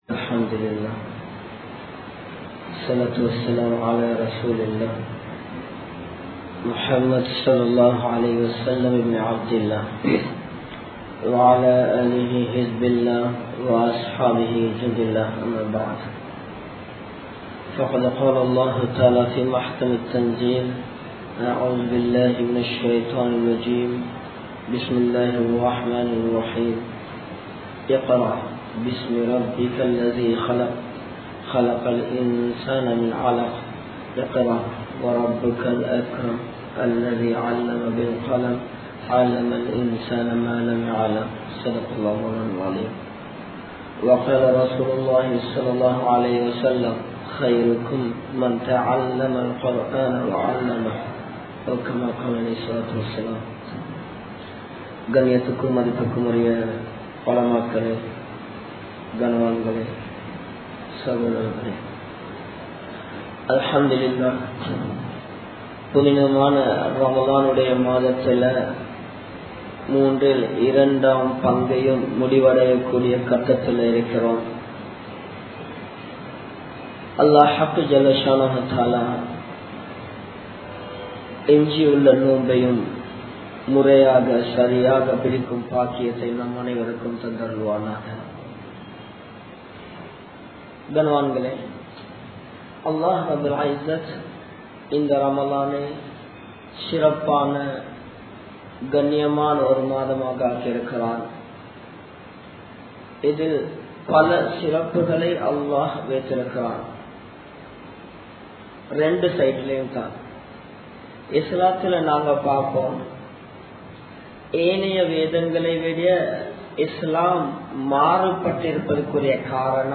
Qatar in Thatpoathaiya Nilai (கட்டாரின் தற்போதைய நிலை) | Audio Bayans | All Ceylon Muslim Youth Community | Addalaichenai
Colombo 11, Samman Kottu Jumua Masjith (Red Masjith)